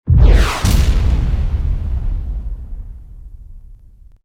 missile1.wav